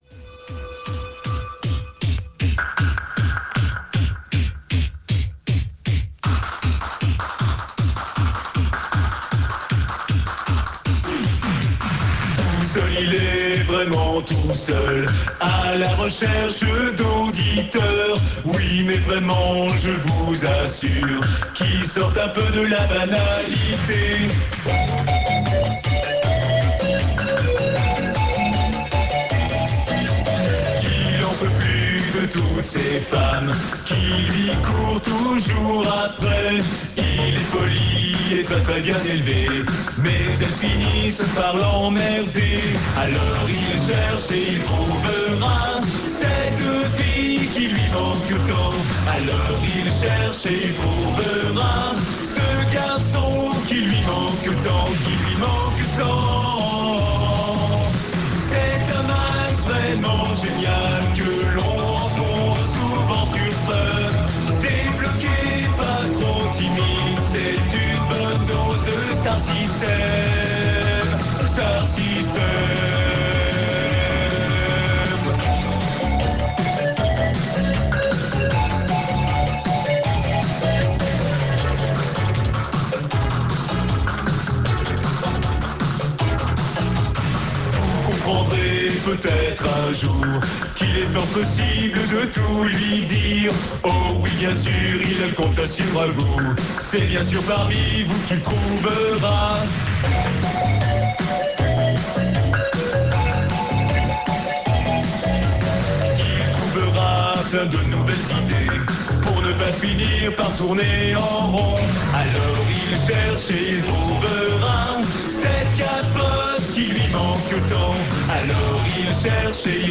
Parodie